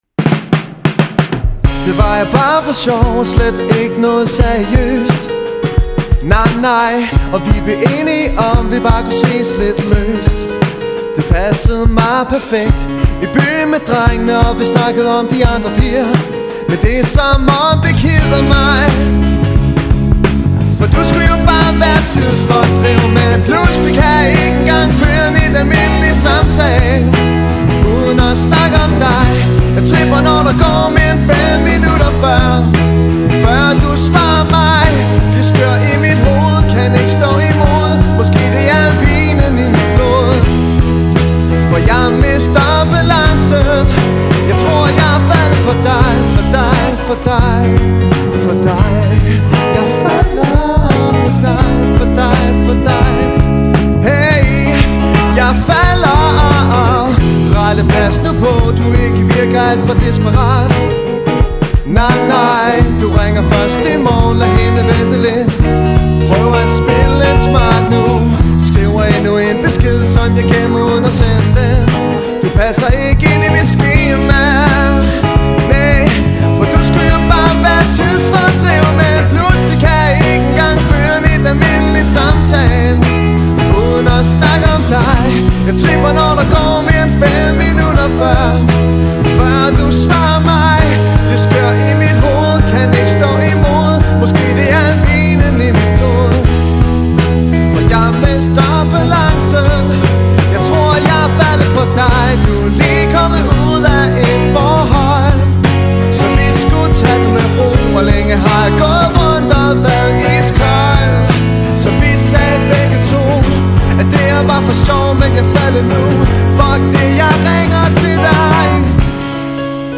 • Allround Partyband
• Coverband
• Rockband